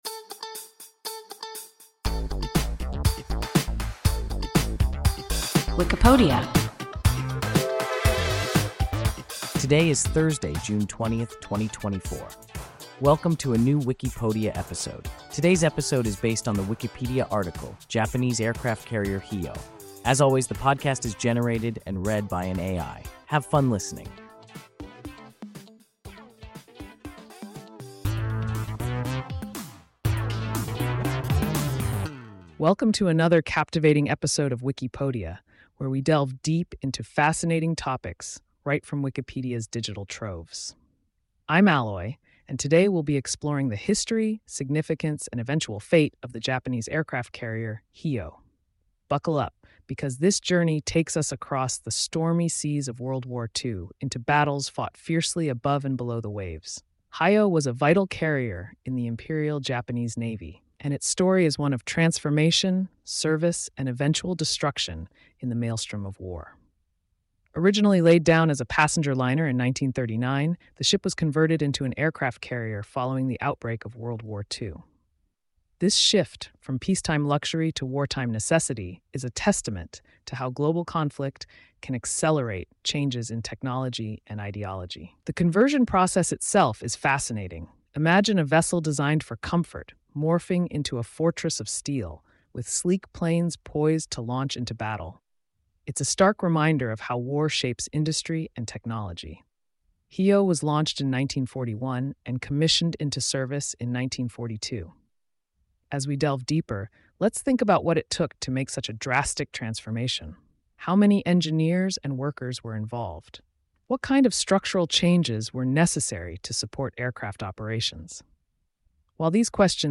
Japanese aircraft carrier Hiyō – WIKIPODIA – ein KI Podcast